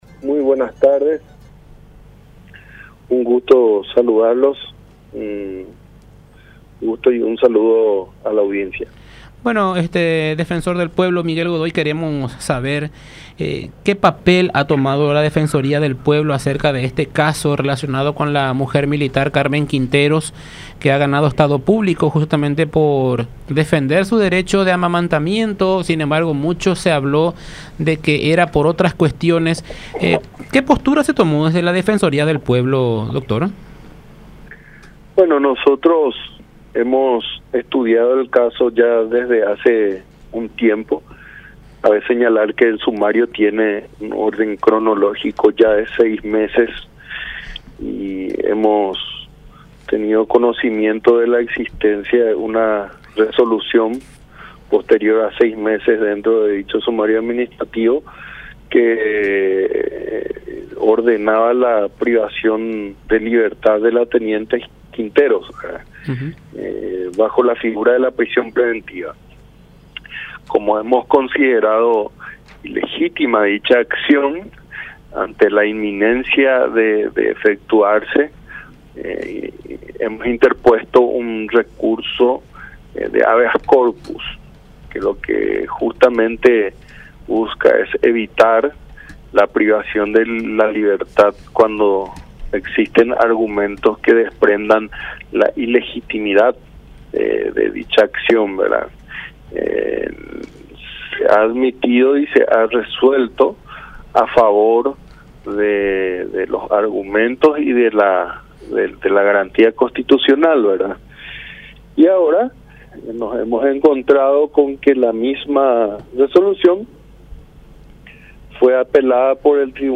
Miguel Godoy, Defensor del Pueblo, habló en contacto con La Unión R800 AM